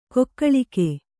♪ kokkaḷike